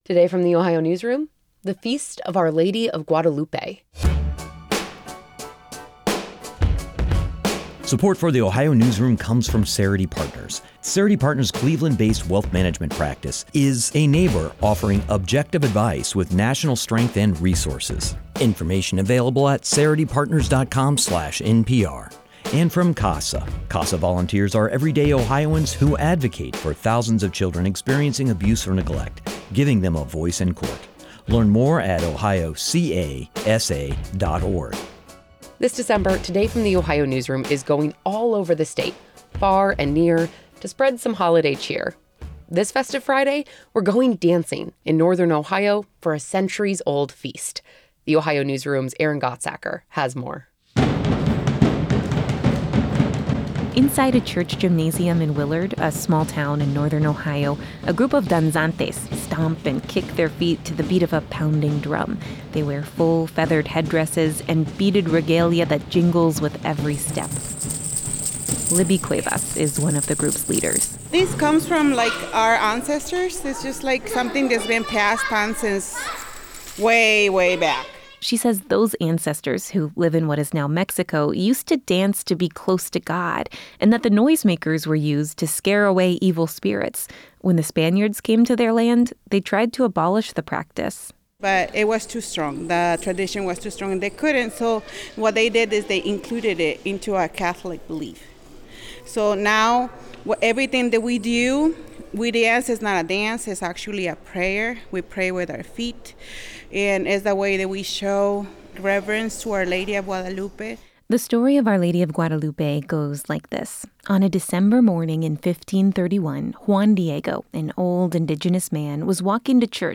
A group of danzantes in northern Ohio practice for a procession for the Feast of Our Lady of Guadalupe.
Dressed in full feathered headdresses and sequined regalia, a group of Mexican folk dancers gathered last week inside a church gymnasium in Willard, a small town in northern Ohio.
To the beat of pounding drums, they stomped and kicked out their feet, the beads on their outfits jingling with every move.